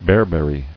[bear·ber·ry]